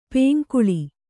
♪ pēŋkuḷi